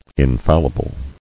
[in·fal·li·ble]